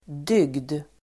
Uttal: [dyg:d]